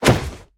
Minecraft Version Minecraft Version snapshot Latest Release | Latest Snapshot snapshot / assets / minecraft / sounds / entity / player / attack / knockback3.ogg Compare With Compare With Latest Release | Latest Snapshot
knockback3.ogg